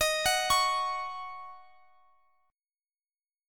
Ebm7 Chord
Listen to Ebm7 strummed